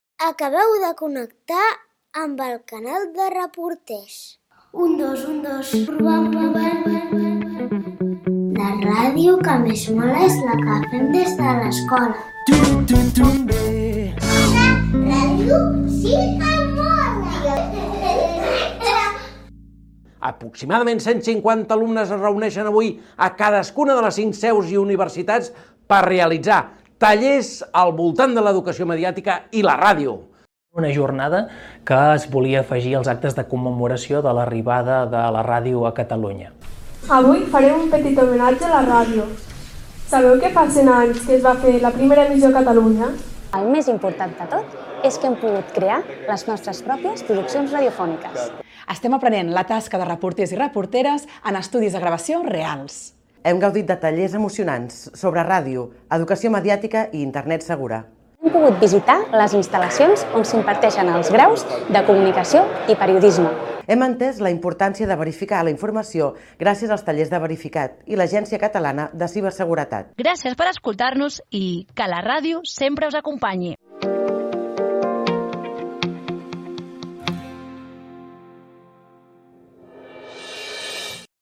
Indicatiu i resum de la II Jornada Canal de Reporters i la Ràdio feta a facultats de comunicació i periodisme de Bellaterra, Mataró, Girona, Lleida i Vic
Infantil-juvenil